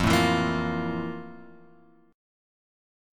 FM11 chord {1 0 2 3 x 1} chord